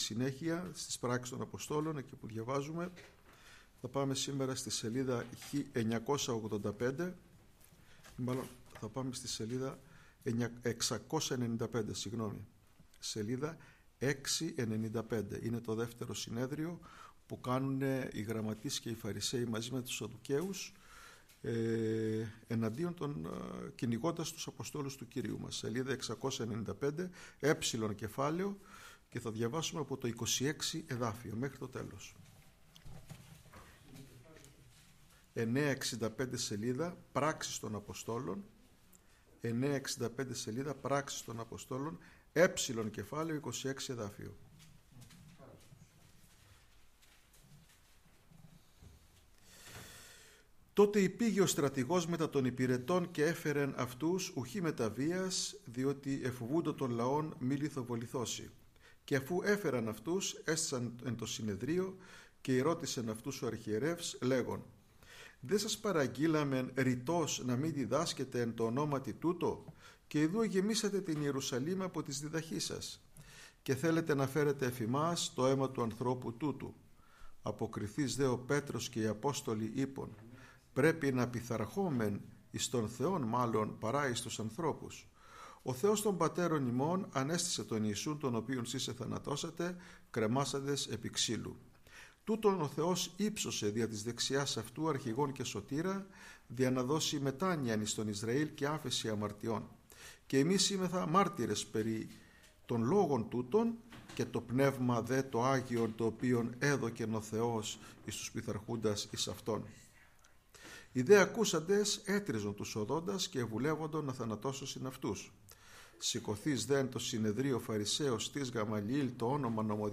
-Κηρυγμα Ευαγγελιου